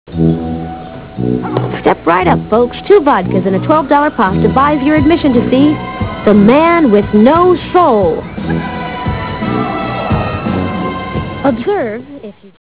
Comment: creepy carnival